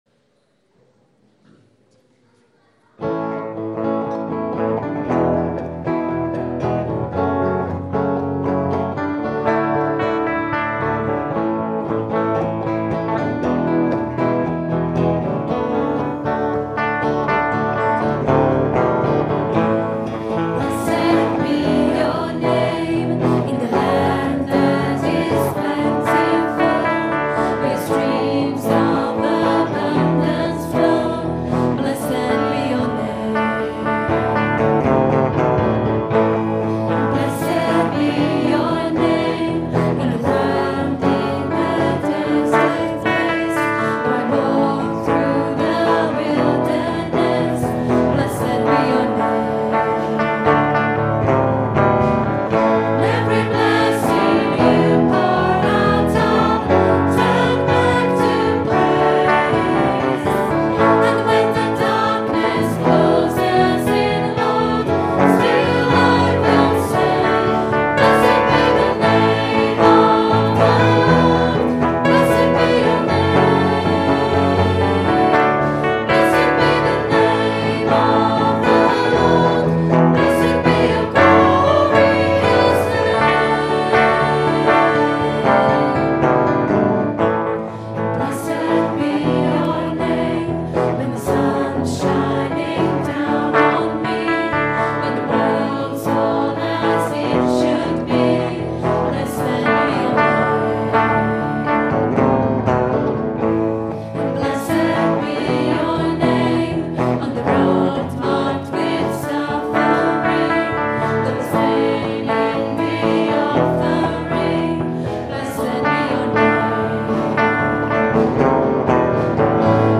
Recorded on a Zoom H4 digital stereo recorder at 10am Mass Sunday 26th September 2010.